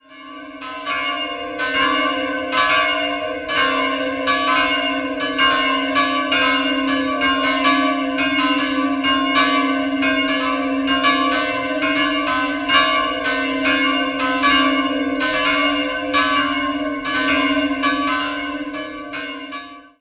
3-stimmiges Geläute: h'-c''-cis'' Die große Glocke wurde im ersten Drittel des 16.